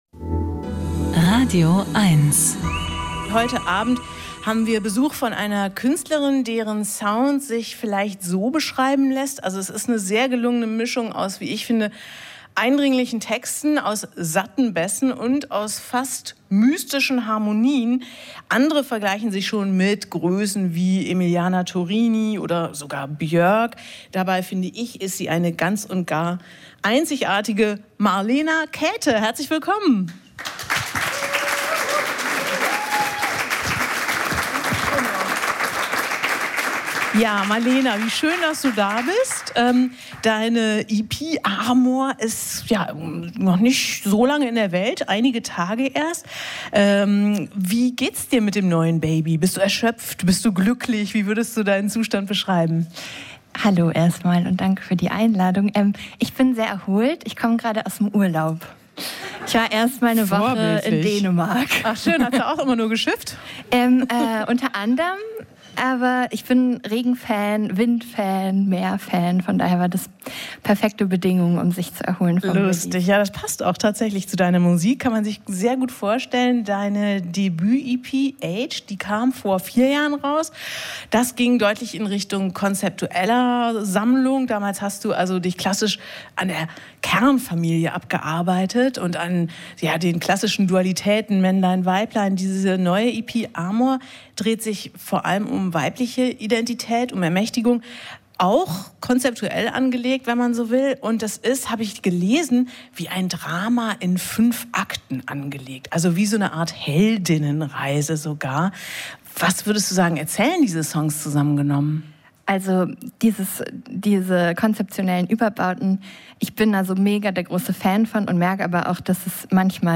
Musik-Interviews
Die besten Musiker im Studio oder am Telefon gibt es hier als Podcast zum Nachhören.